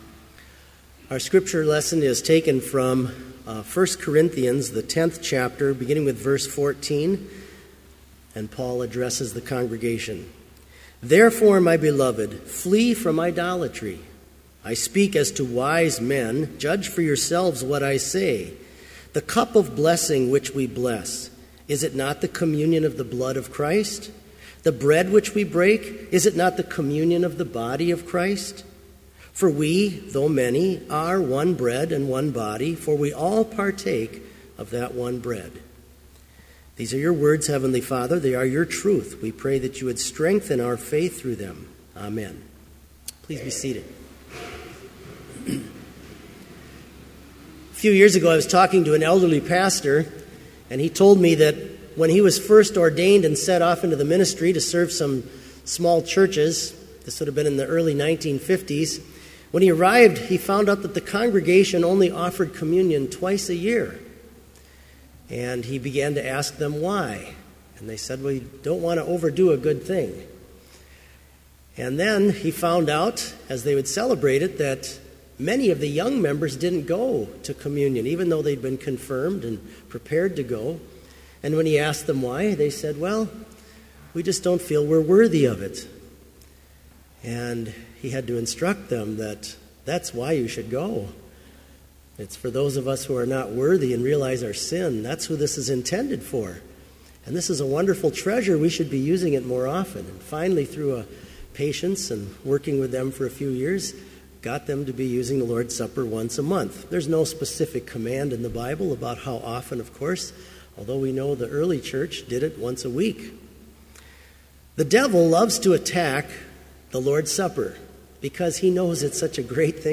Complete Service
This Chapel Service was held in Trinity Chapel at Bethany Lutheran College on Friday, February 13, 2015, at 10 a.m. Page and hymn numbers are from the Evangelical Lutheran Hymnary.